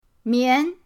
mian2.mp3